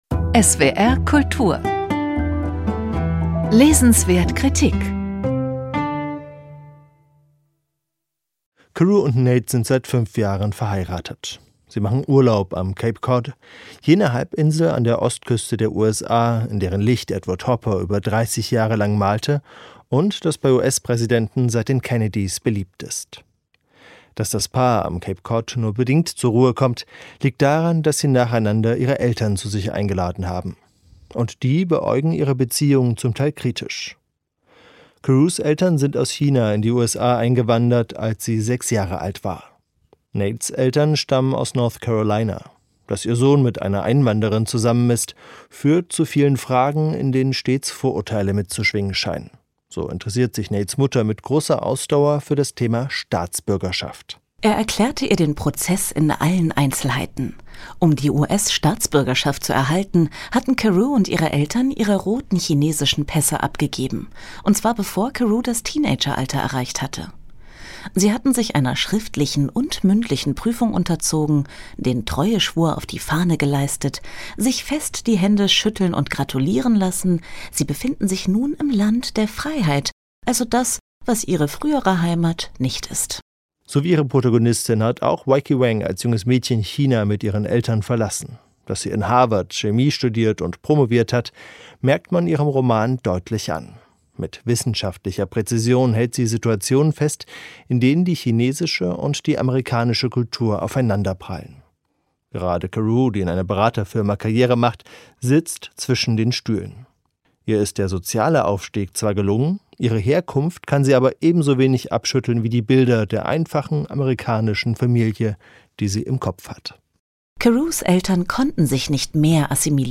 Weike Wang – Die Ferien | Buchkritik